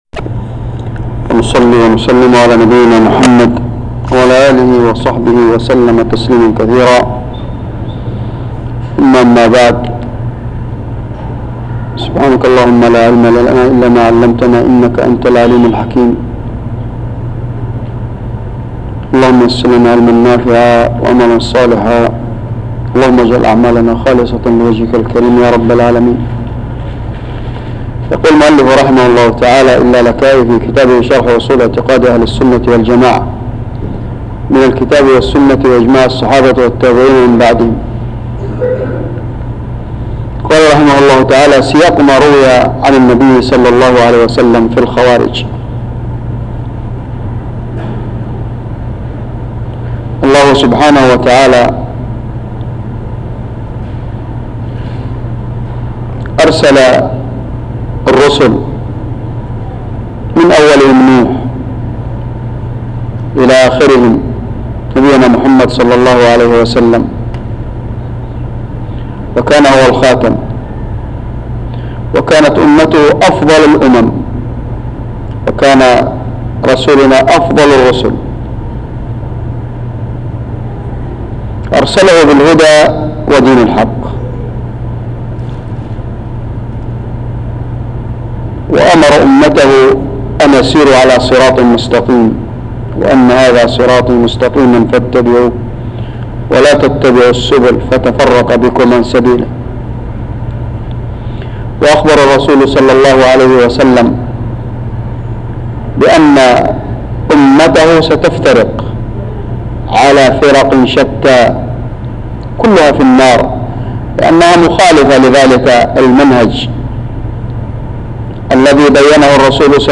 تسجيل لدروس شرح أصول اعتقاد أهل السنة والجماعة للألكائي - الجزء السابع